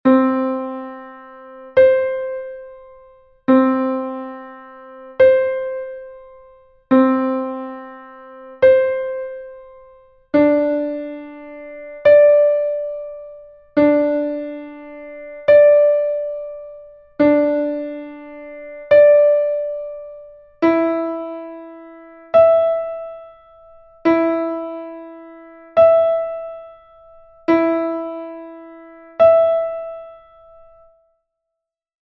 8ve practice
practica_previa_intervalos_octava.mp3